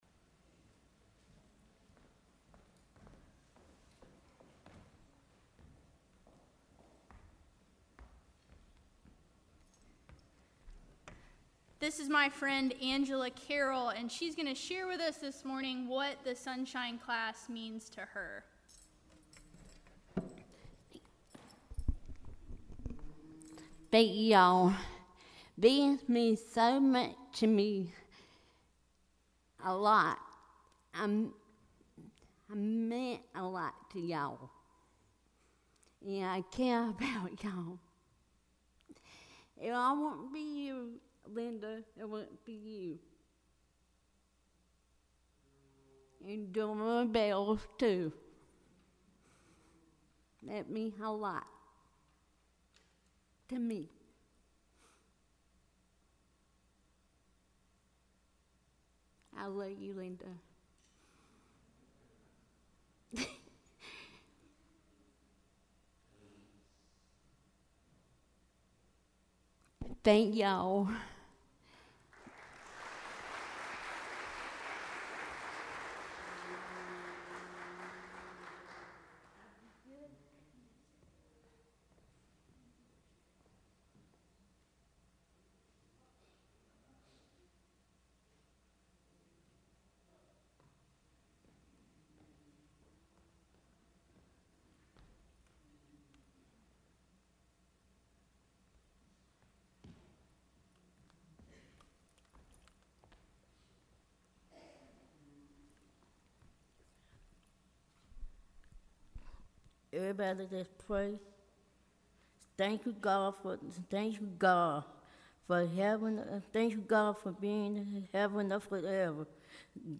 Today was the annual SonShine Sunday, during which the class leads us in worship. Several voices you will hear on this audio are members of the class.